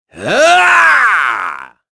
Crow-Vox_Casting4.wav